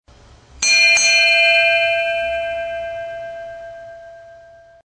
Ships bell